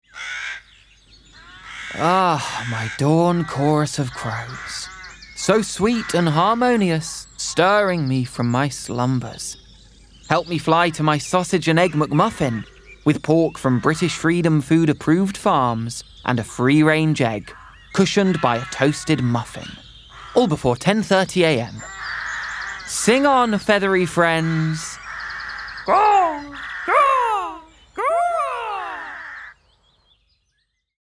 McDonalds - Animated, Quirky, Playful